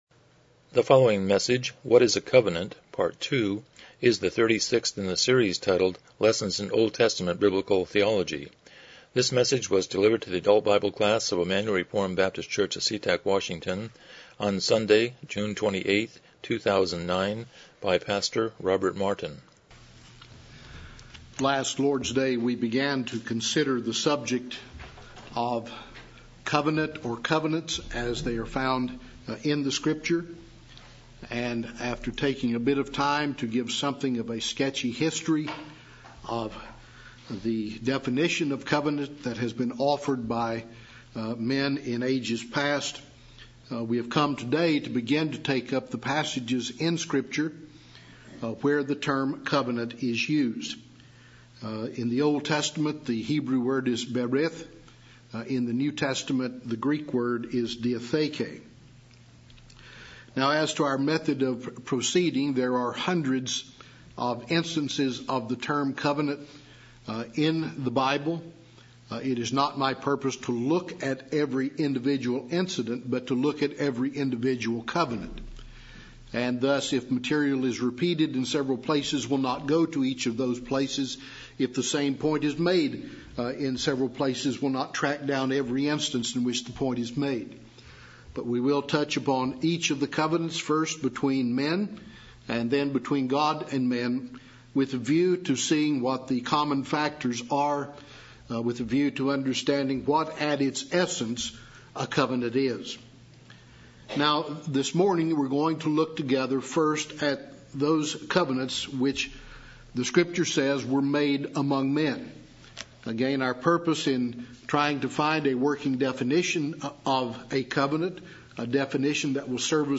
Lessons in OT Biblical Theology Service Type: Sunday School « 65 Chapter 11.1